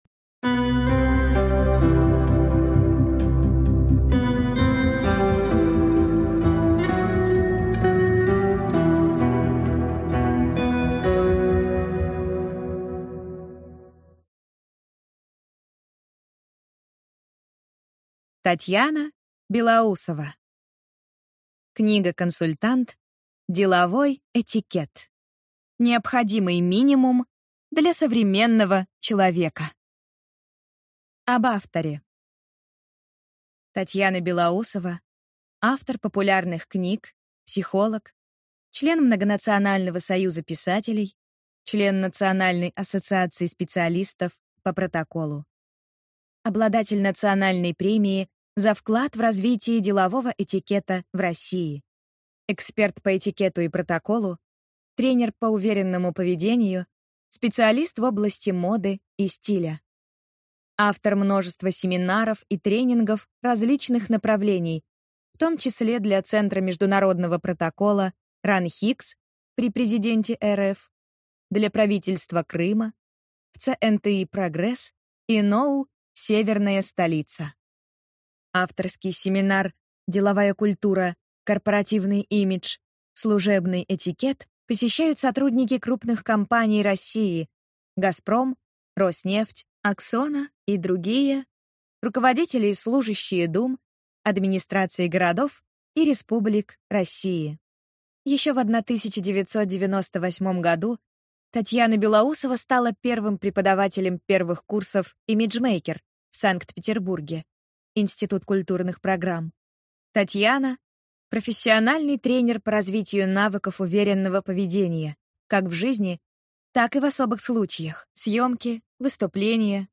Аудиокнига Деловой этикет.